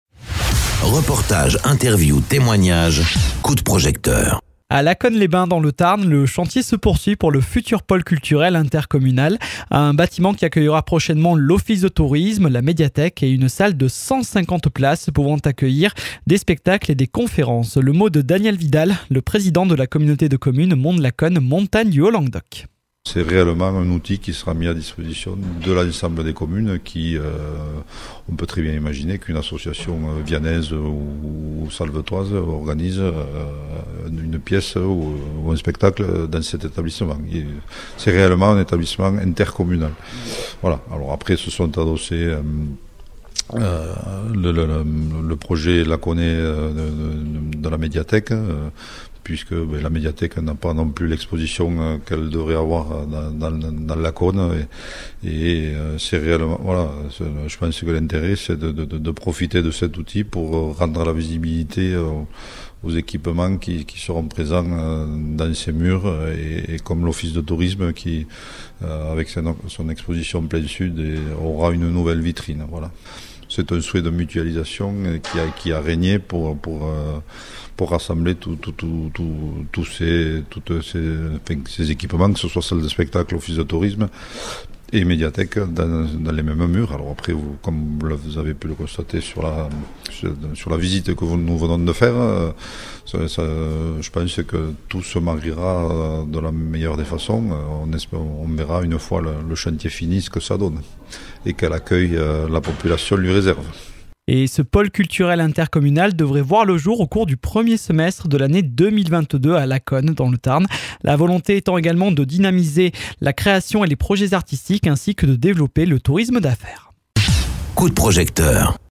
Interviews
Invité(s) : Daniel Vidal, le président de la communauté de communes Monts de Lacaune Montagne du Haut Languedoc.